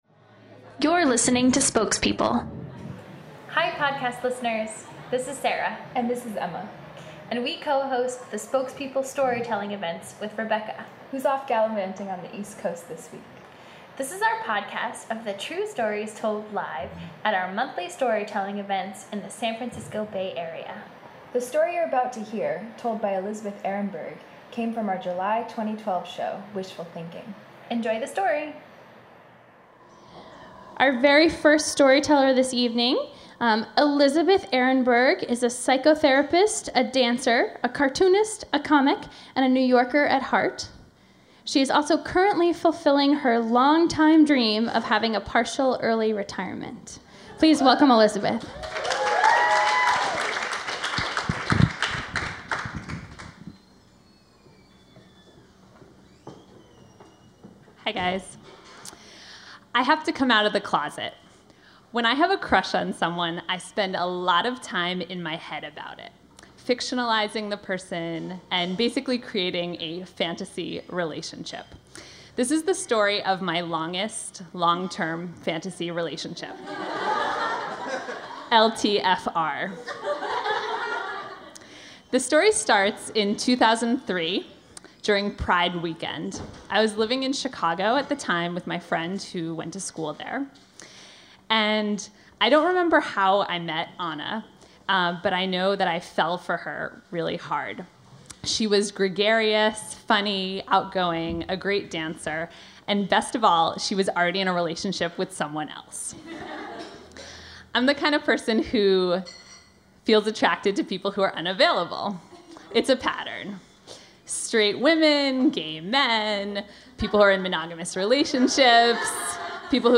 Homepage / Podcast / Storytelling
This story of love (un)requited came from our July 2012 show “Wishful Thinking.”